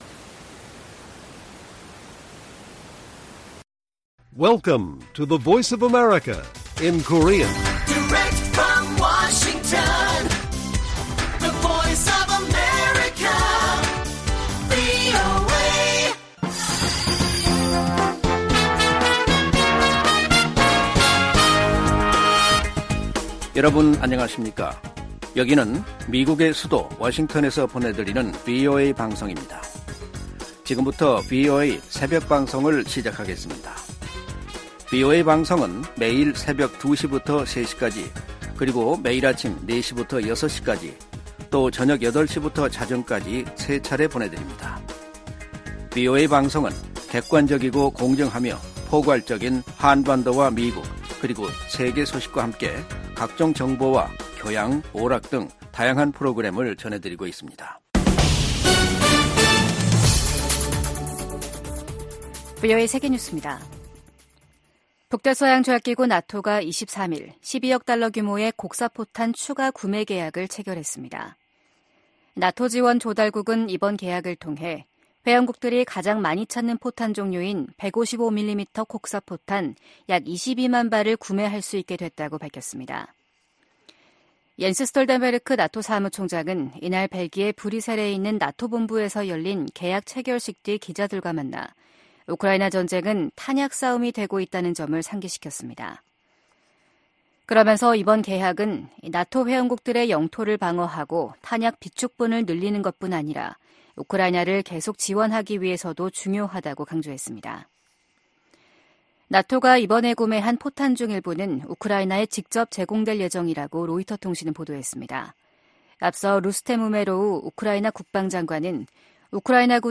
VOA 한국어 '출발 뉴스 쇼', 2024년 1월 24일 방송입니다. 북한-러시아 군사협력은 역내 안정과 국제 비확산 체제를 약화시킨다고 유엔 주재 미국 차석대사가 지적했습니다. 백악관이 북한과 러시아의 무기 거래를 거론하며 우크라이나에 대한 지원의 필요성을 강조했습니다. 중국에 대한 보편적 정례 인권 검토(UPR)를 앞두고 유엔과 유럽연합(EU)이 탈북민 강제 북송 중단을 중국에 촉구했습니다.